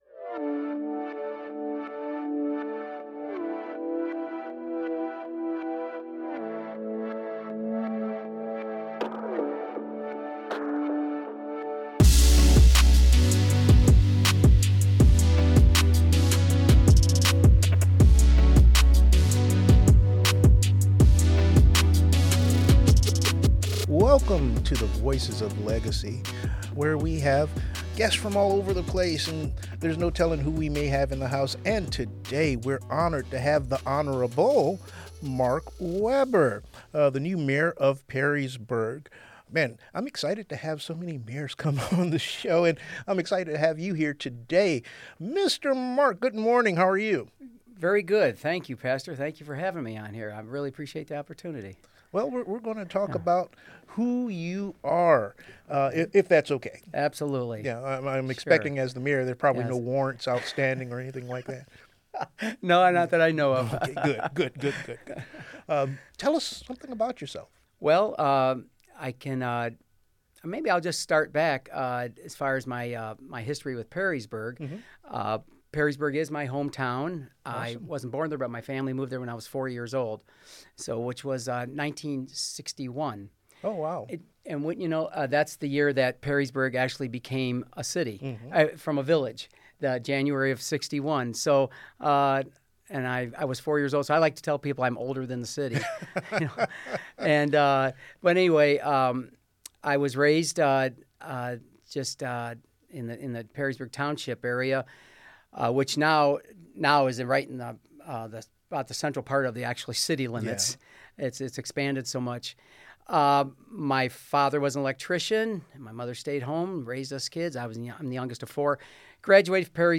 Mayor Weber shares his heart for public service, his vision for a safe, welcoming, and thriving community, and the values guiding his leadership as he begins this new chapter. This thoughtful conversation highlights the importance of connection, responsibility, and legacy in local government—and the people it serves.